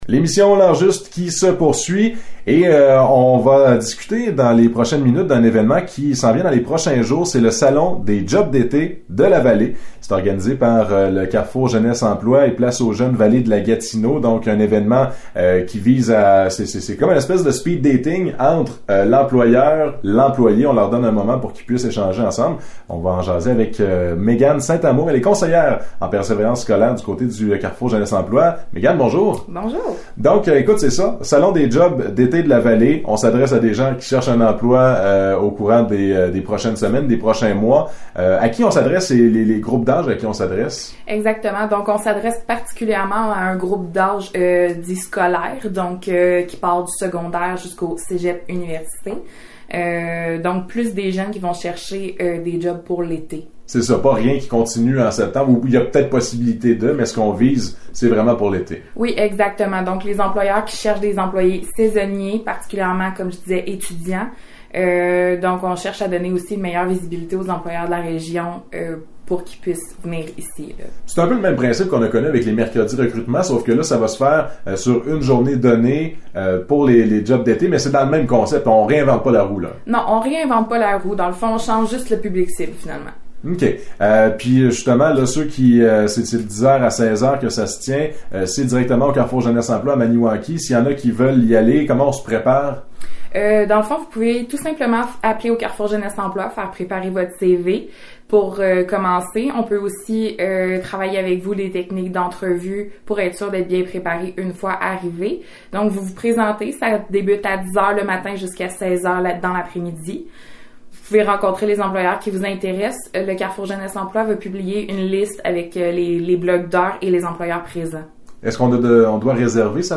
Entrevues